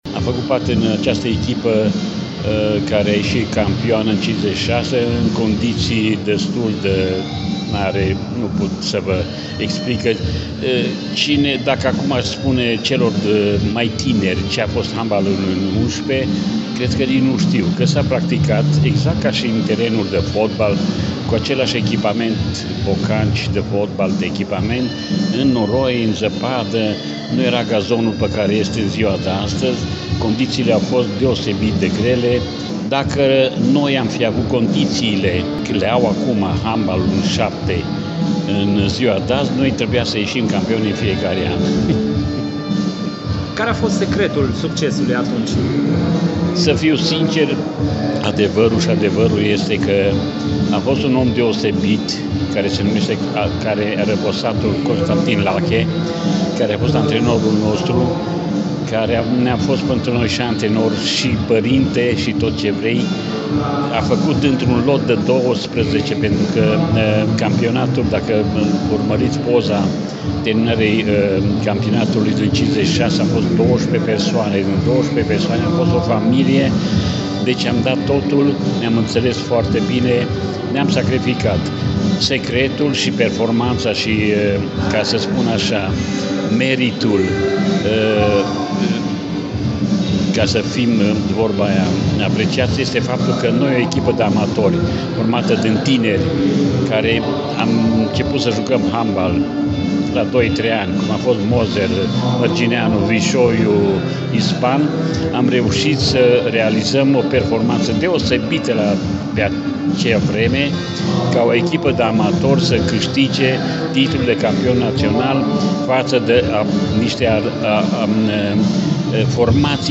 Foștii și actualii handbaliști ai Timișoarei au petrecut împreună o seară frumoasă cu amintiri, zâmbete și ”un pahar de vorbă”, cu prilejul aniversării a 70 de ani de la începutul activității acestui sport în cadrul Școlii Politehnice.